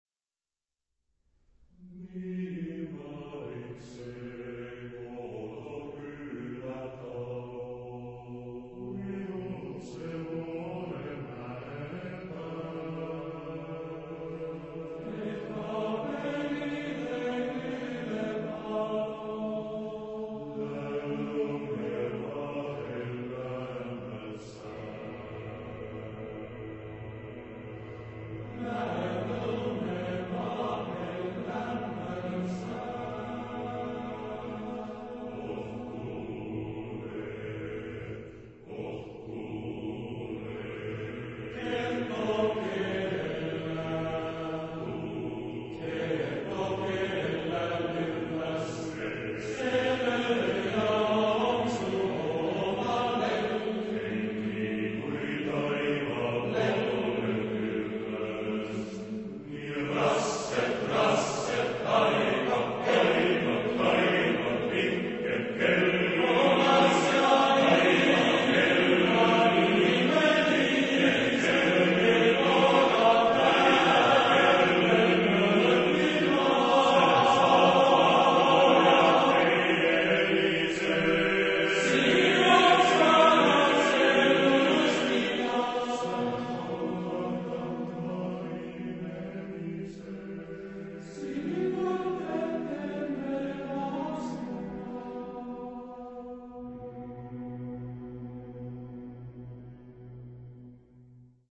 Genre-Style-Forme : Sacré ; Pièce vocale
Type de choeur : TTBB  (4 voix égales d'hommes )
Solistes : Ténor (1)  (1 soliste(s))